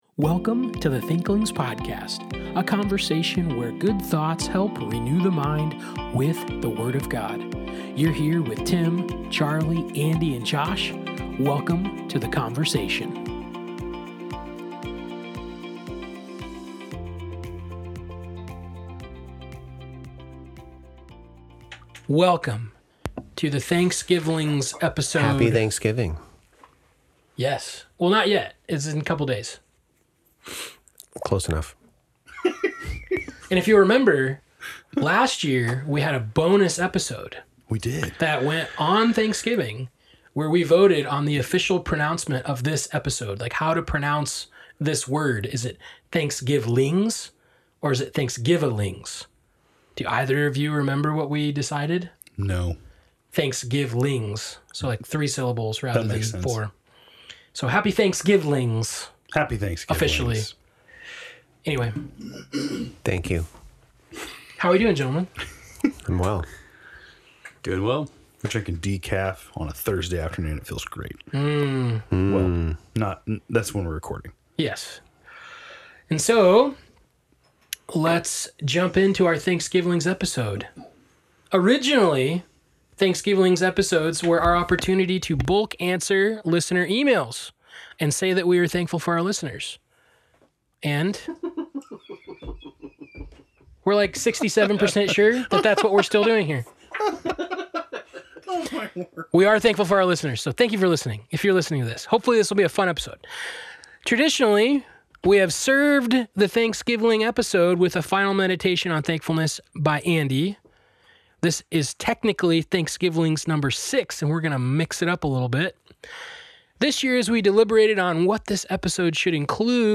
Thanks for joining us for this year’s festive and lighthearted conversation!